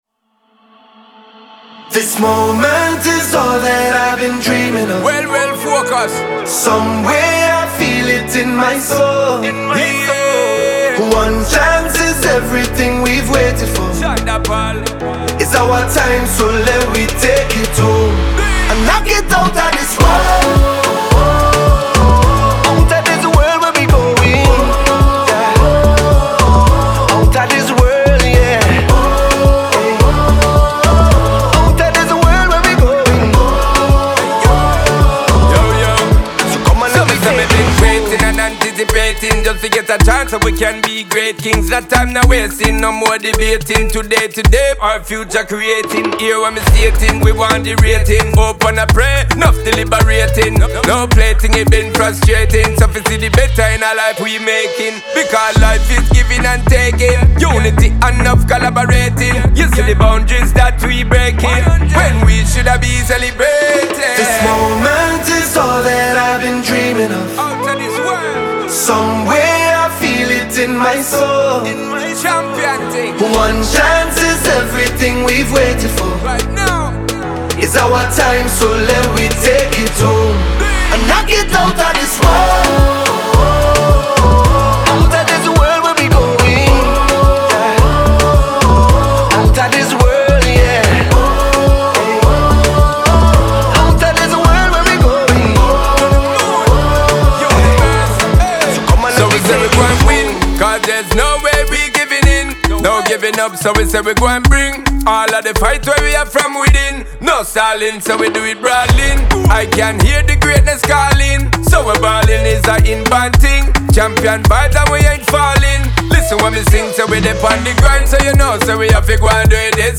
• Жанр: Pop, Reggae